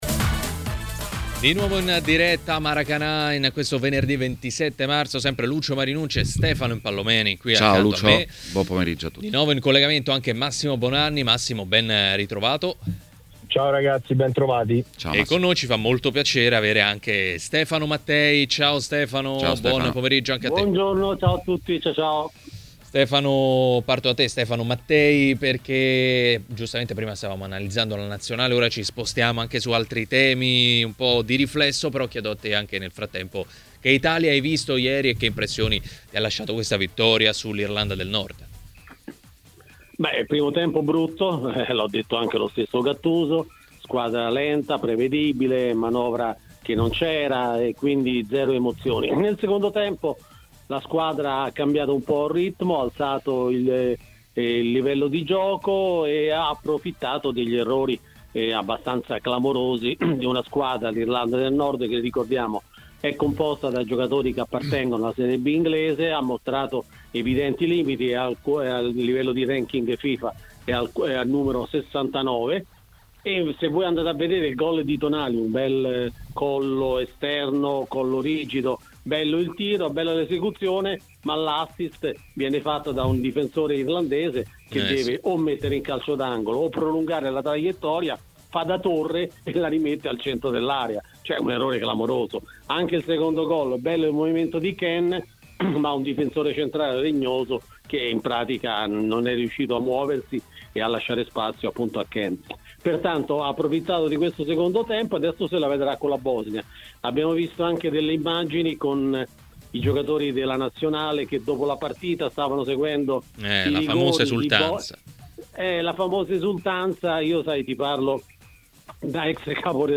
Ospite di Maracanà, nel pomeriggio di TMW Radio, è stato il giornalista